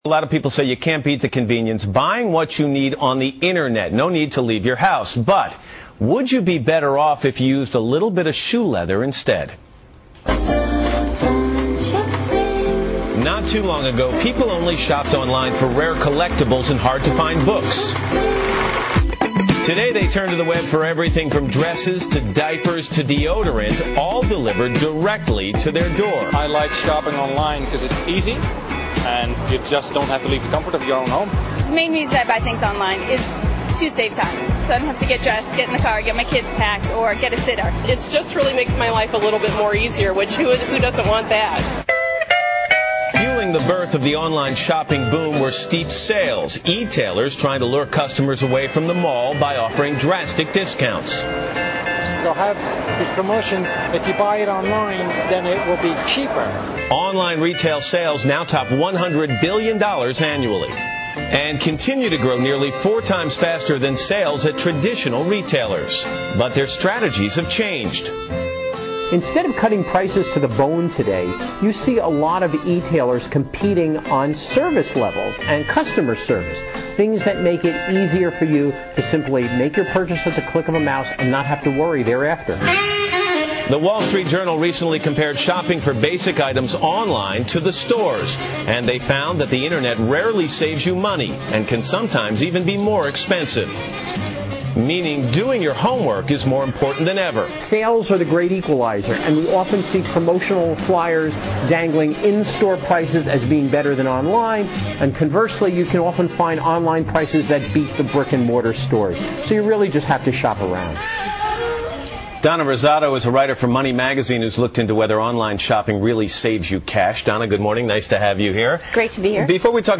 访谈录 Interview 2007-07-28&07-30, 网上购物真省钱？ 听力文件下载—在线英语听力室